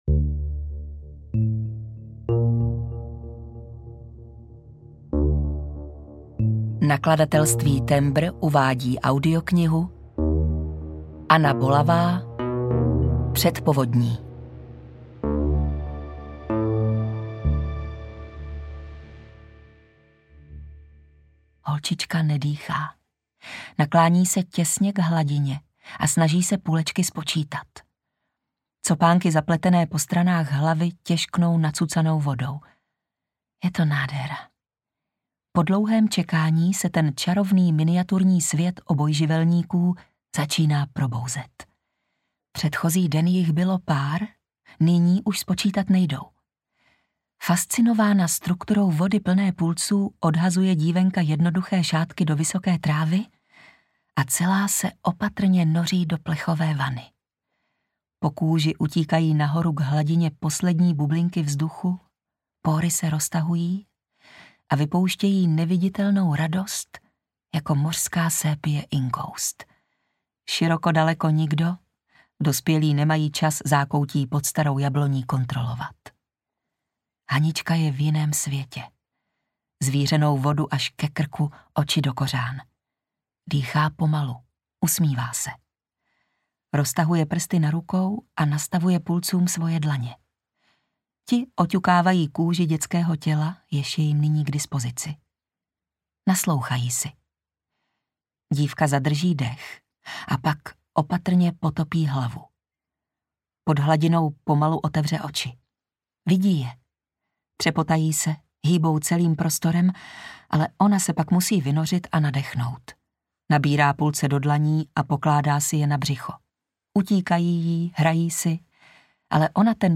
Před povodní audiokniha
Ukázka z knihy
pred-povodni-audiokniha